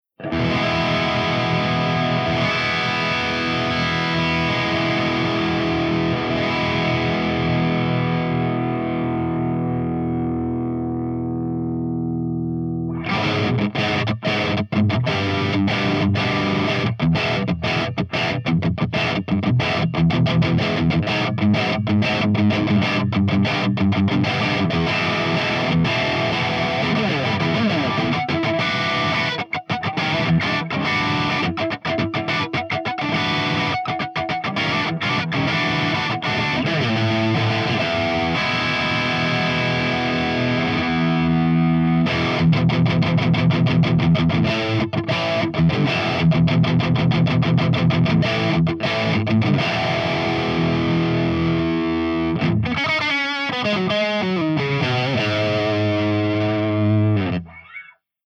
159_EVH5150_CH2HIGHGAIN_V30_SC